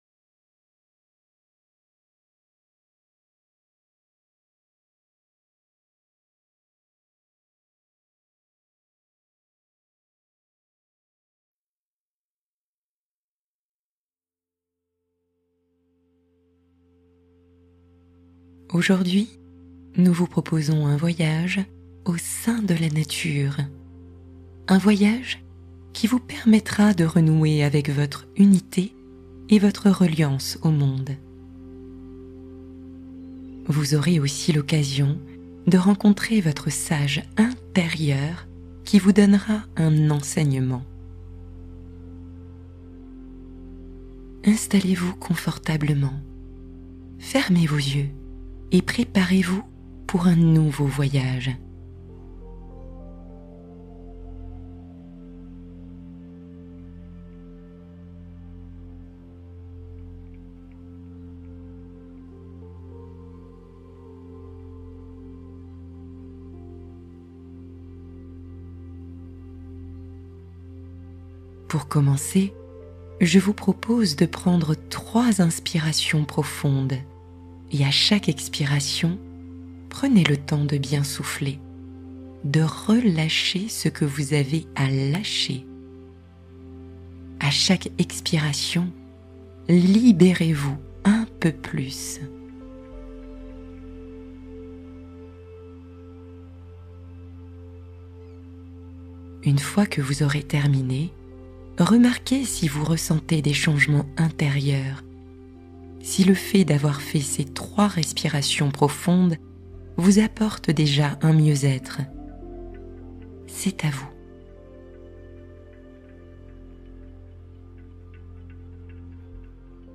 Clarifier une situation : guidance douce pour retrouver apaisement et lucidité